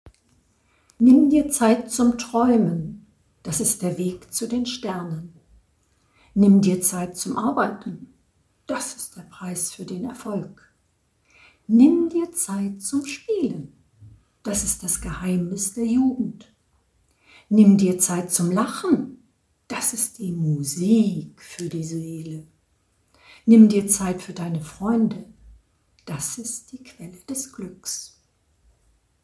10 Nimm dir Zeit - eine kleine Weihnachtsgeschichte